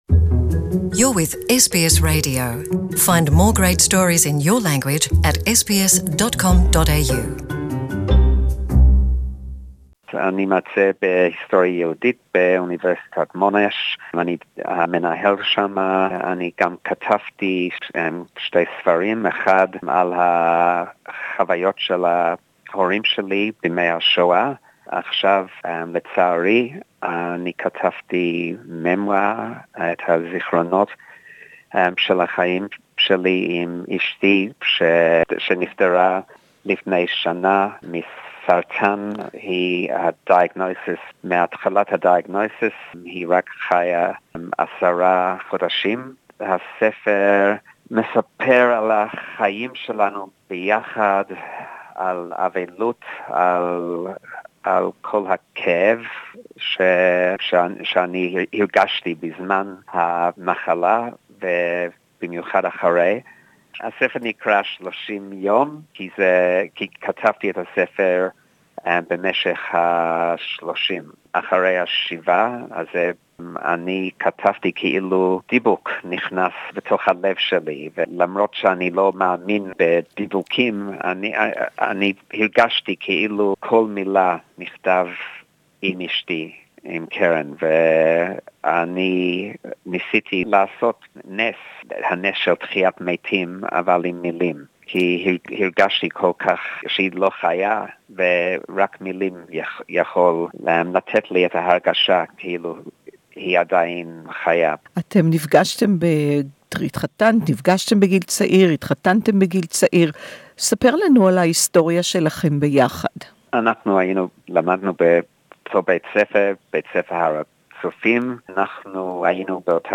about his book “30 Days” Interview in Hebrew